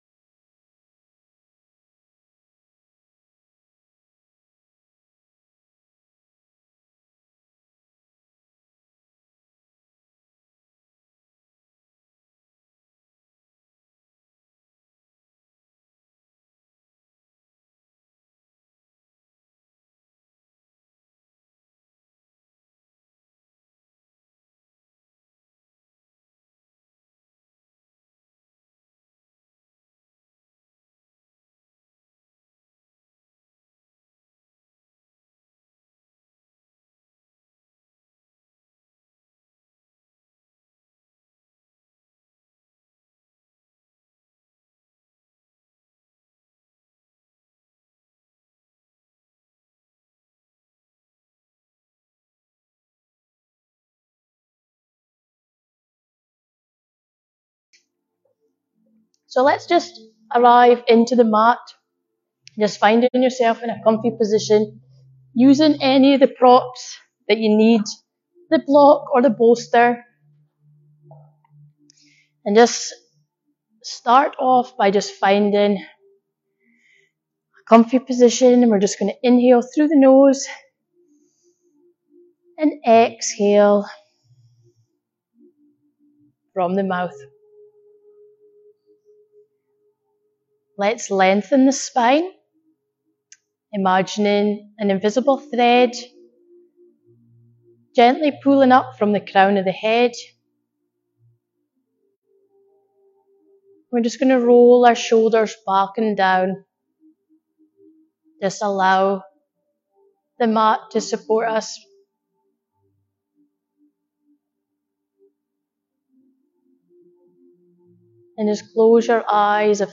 1 hour grounding yoga practice for women
Welcome to this guided grounding practice that will help you relax and connect with your body.